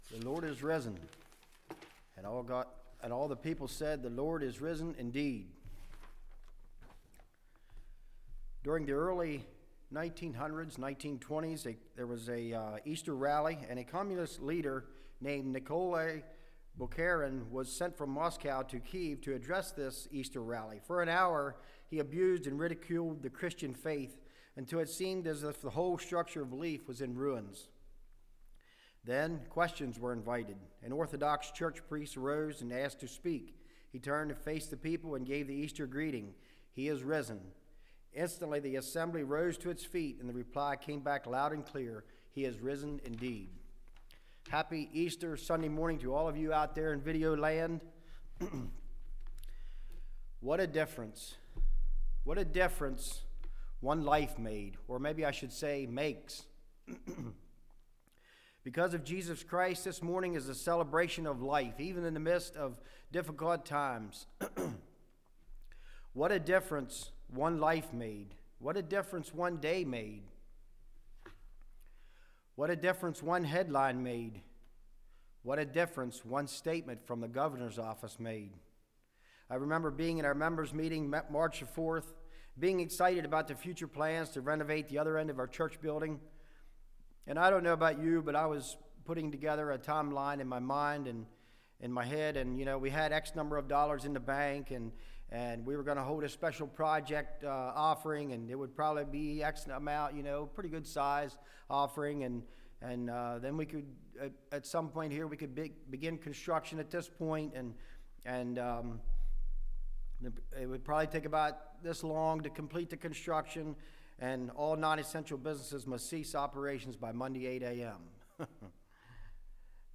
Service Type: Message